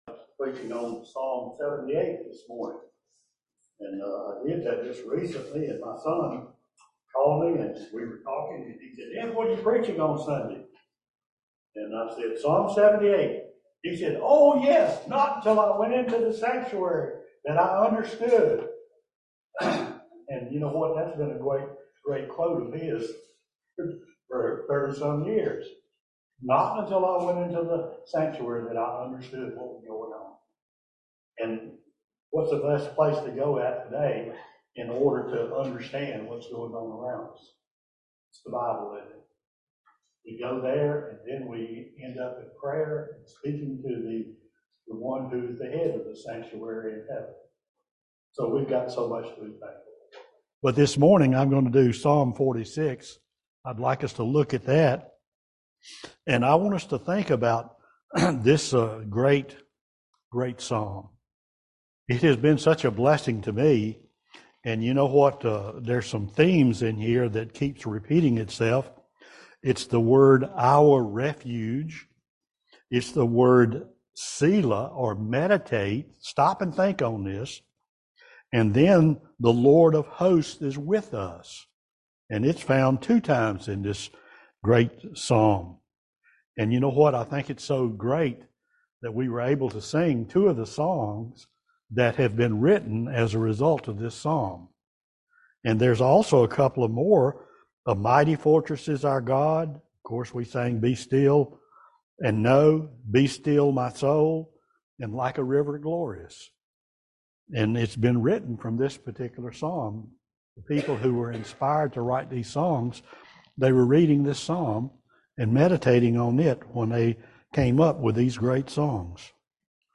Psalm 46 Service Type: Family Bible Hour God is a refuge and a strength.